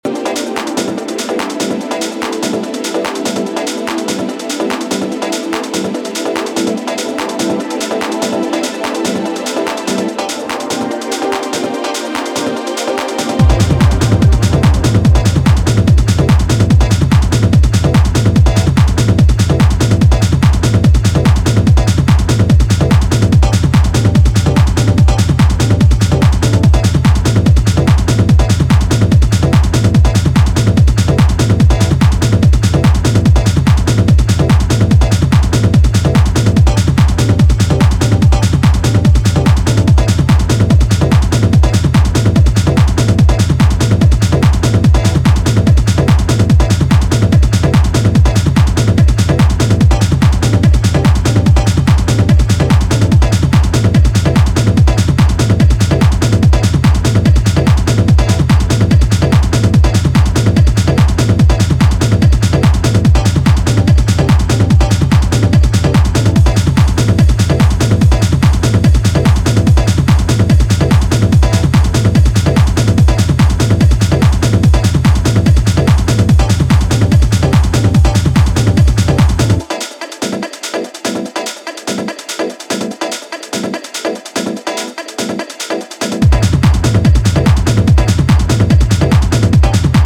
classic, groove-driven techno productions